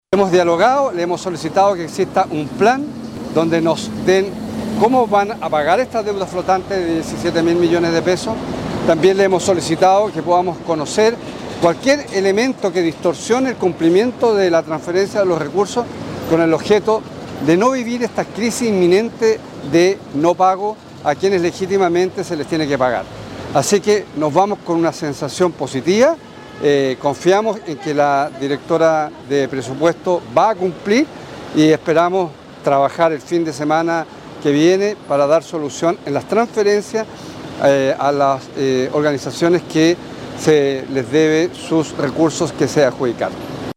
El Gobernador también subrayó la importancia de la transparencia en este proceso, al silicitar un plan detallado con plazos y condiciones de pago, para evitar retrasos inesperados.